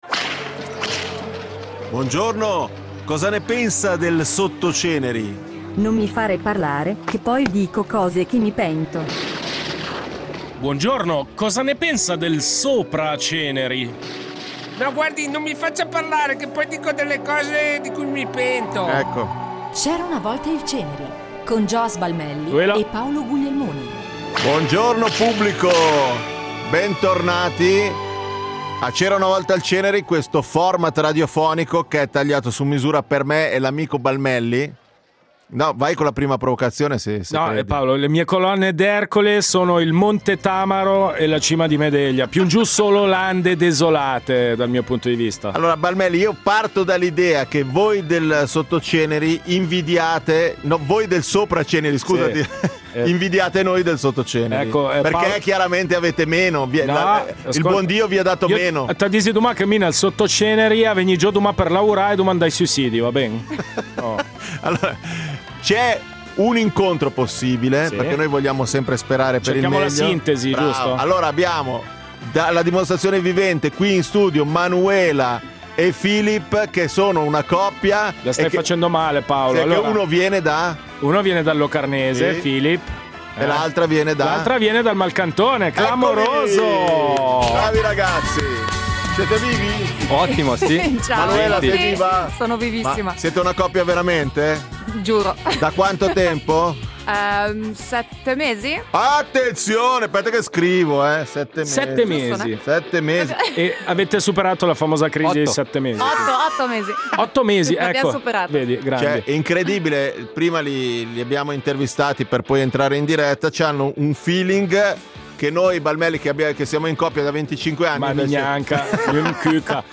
In studio una coppia